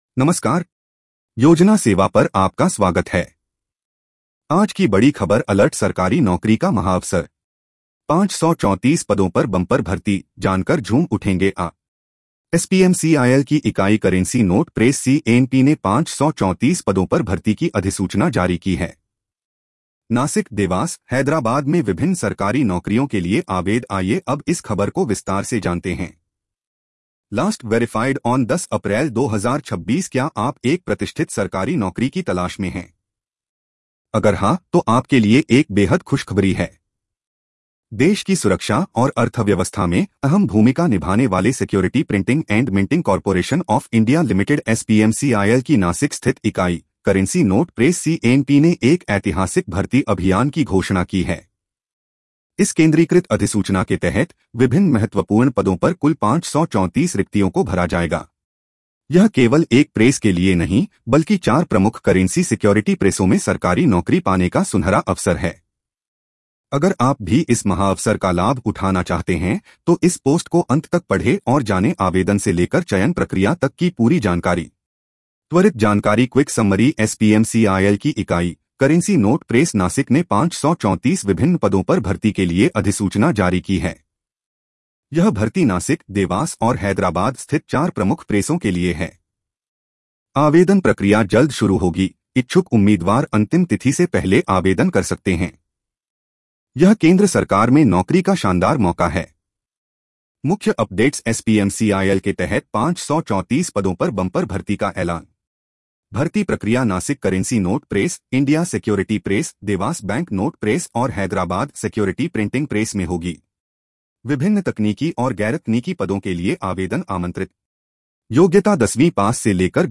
News Audio Summary
इस खबर को सुनें (AI Audio):